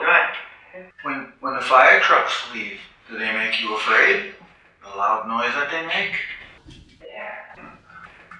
THREE KNOCKS ON/IN THE WALL TAVERN (BASEMENT) 7/9/11 EVP The tavern area is on street level and directly across the street is a Coventry fire station.